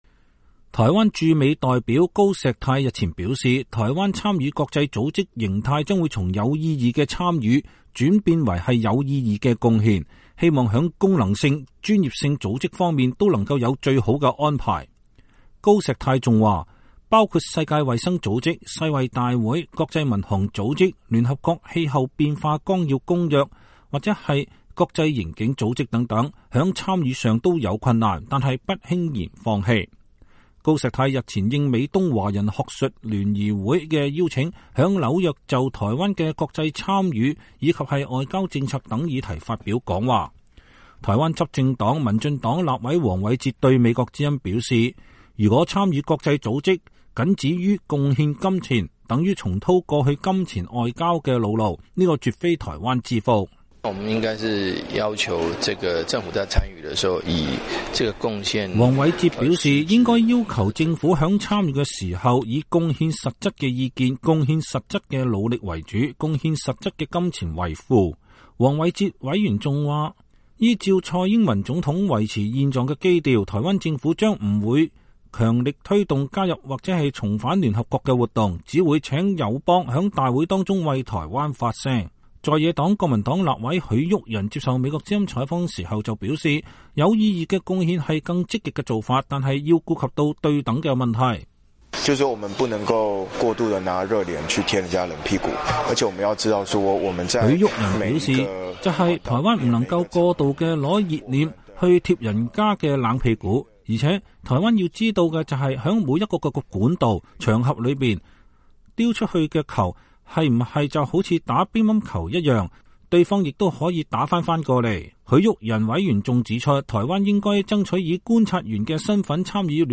在野黨國民黨立委許毓仁接受美國之音採訪表示，有意義的貢獻是更積極的做法，但是要顧及對等的問題。